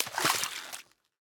Minecraft Version Minecraft Version 25w18a Latest Release | Latest Snapshot 25w18a / assets / minecraft / sounds / block / cauldron / dye2.ogg Compare With Compare With Latest Release | Latest Snapshot
dye2.ogg